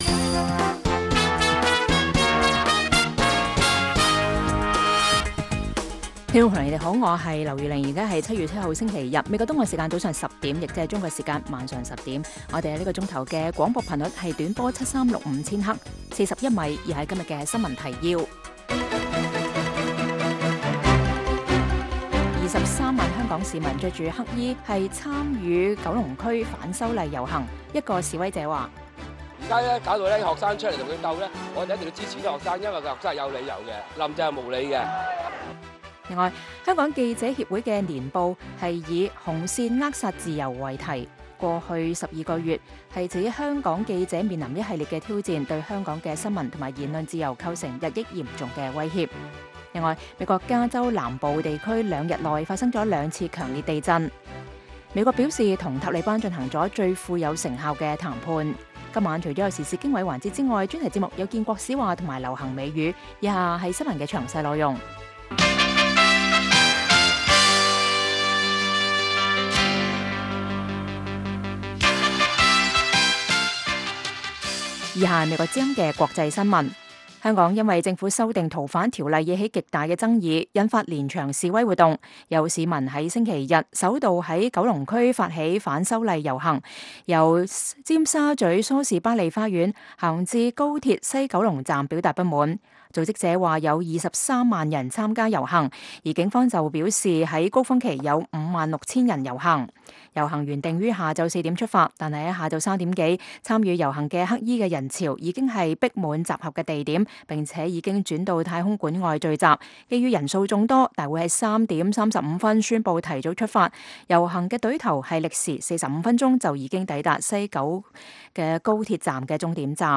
粵語新聞 晚上10-11點
北京時間每晚10－11點 (1400-1500 UTC)粵語廣播節目。內容包括國際新聞、時事經緯和英語教學。